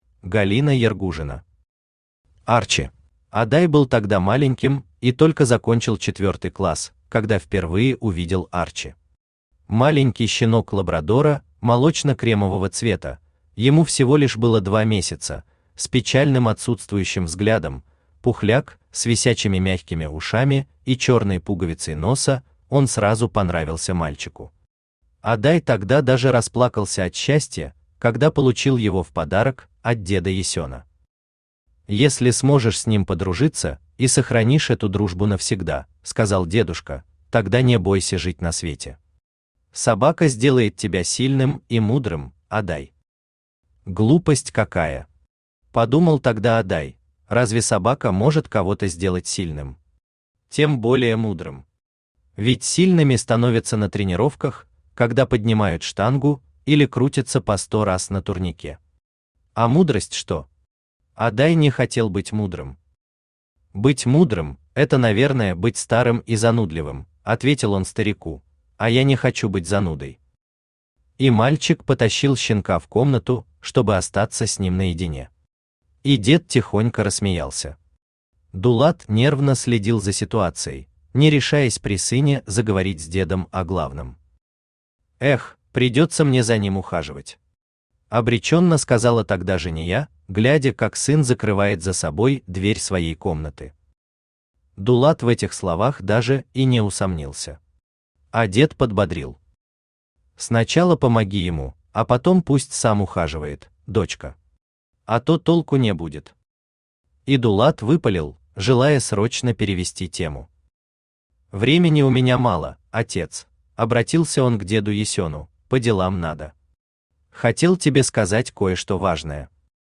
Aудиокнига Арчи Автор Галина Ергужина Читает аудиокнигу Авточтец ЛитРес.